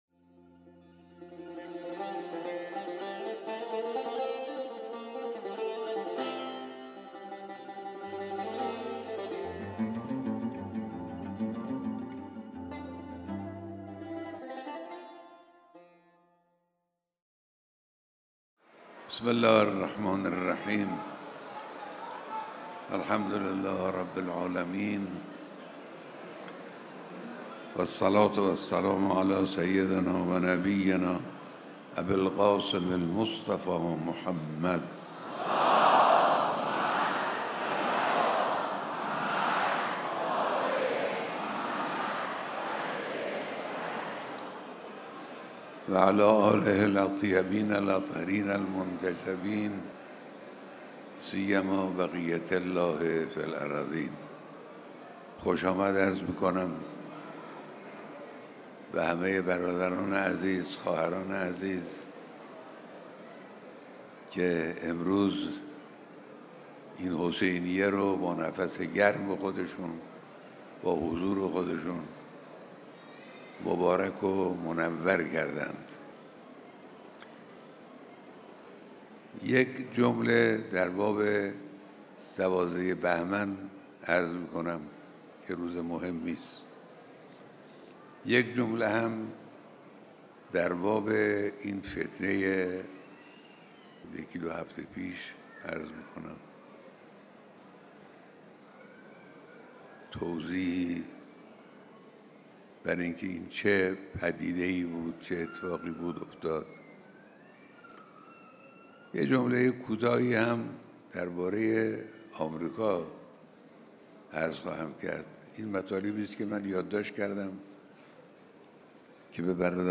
بیانات در دیدار هزاران نفر از اقشار مختلف مردم و در اولین روز دهه فجر